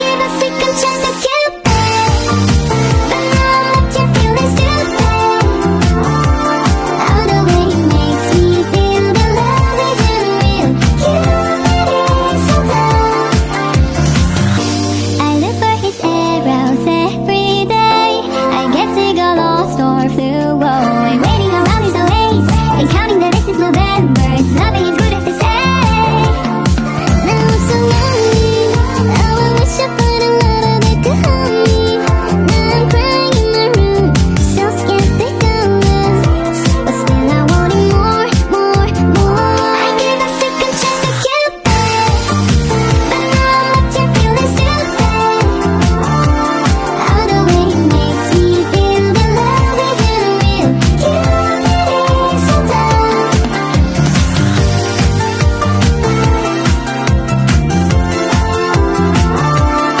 That explains why my keyboard lights up & plays preschool tunes.